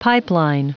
Prononciation du mot pipeline en anglais (fichier audio)
Prononciation du mot : pipeline